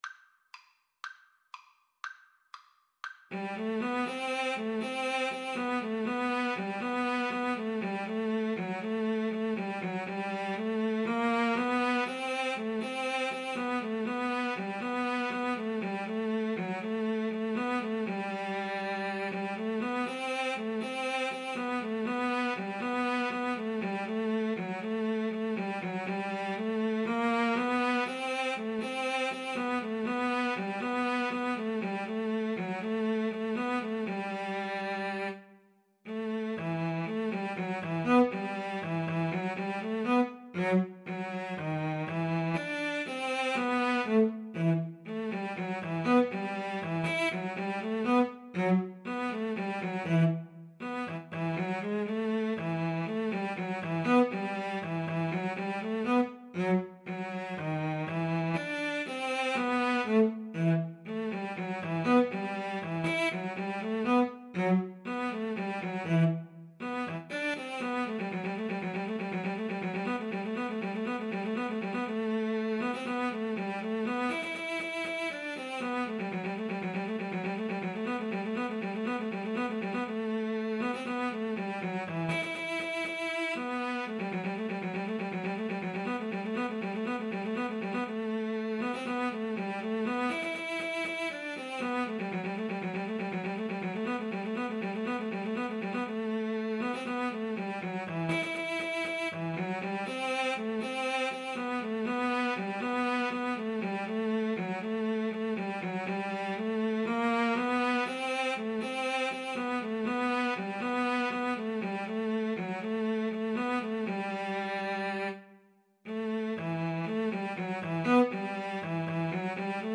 Cello 1Cello 2
E minor (Sounding Pitch) (View more E minor Music for Cello Duet )
Fast Two in a Bar =c.120